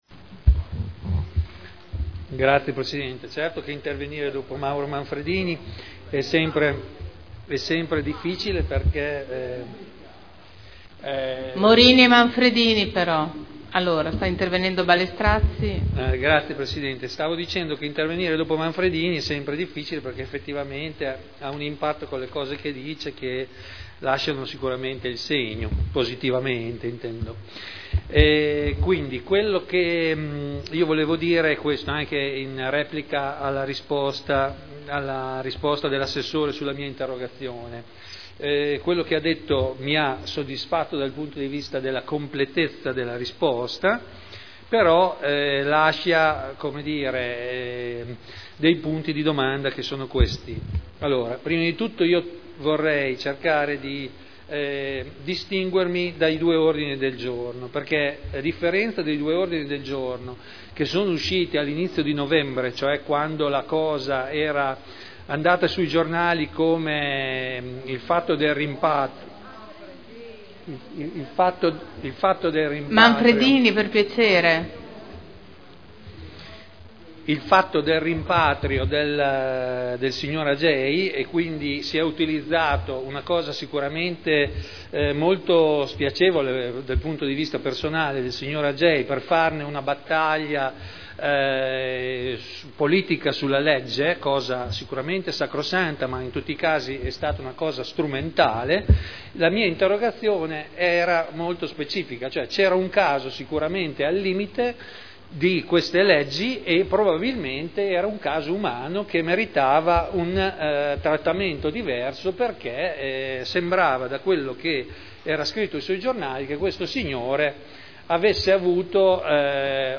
Seduta del 11/11/2010.